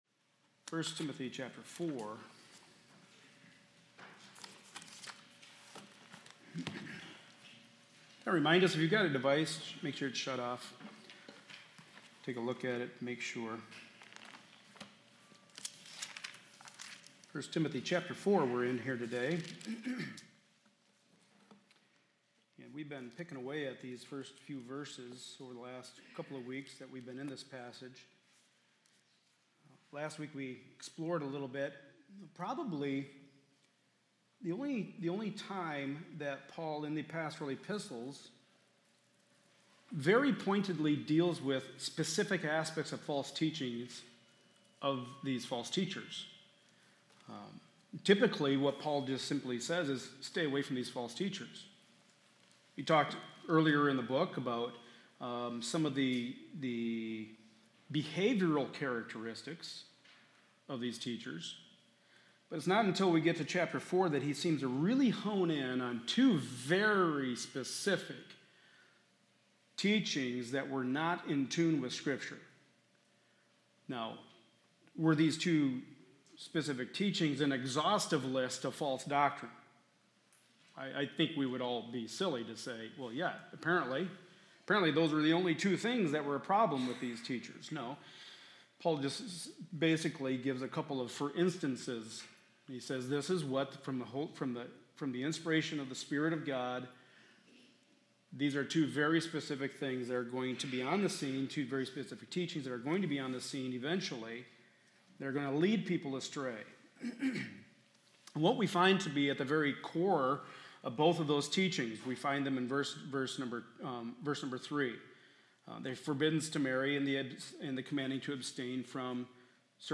1 Timothy 4 Service Type: Sunday Morning Service A study in the Pastoral Epistles.